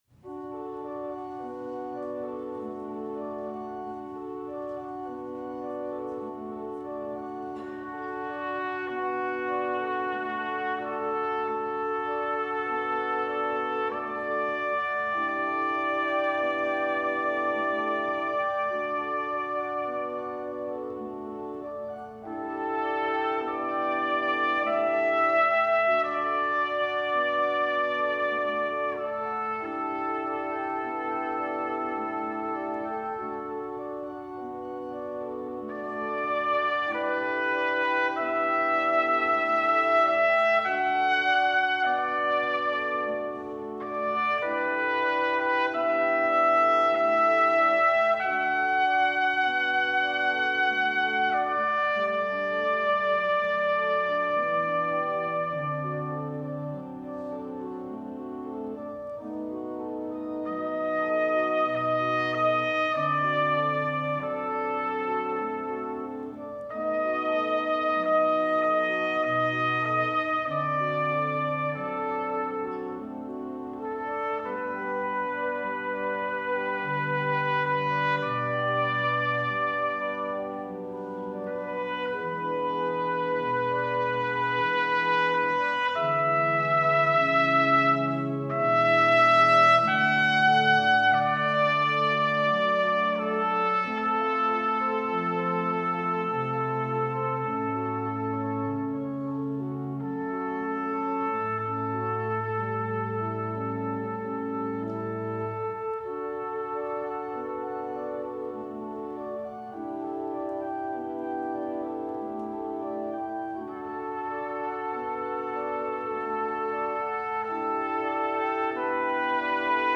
trumpet
organ